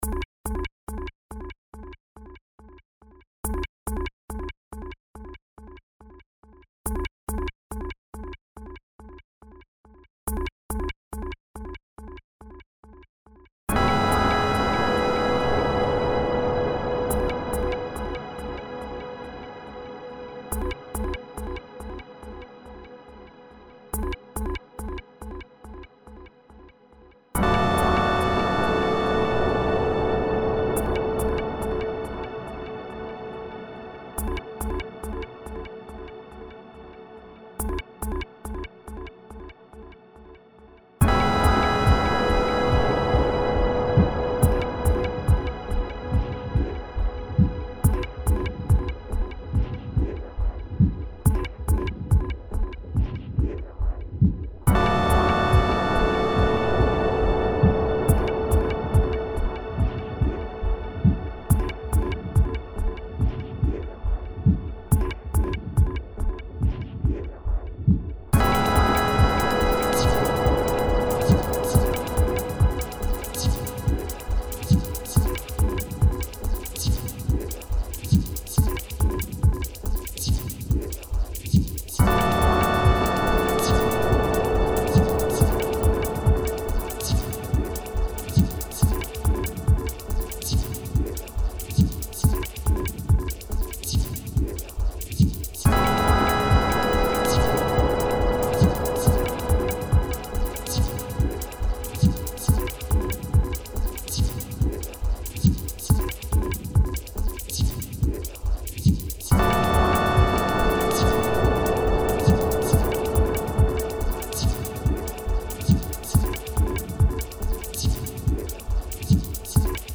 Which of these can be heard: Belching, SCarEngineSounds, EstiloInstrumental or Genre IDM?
Genre IDM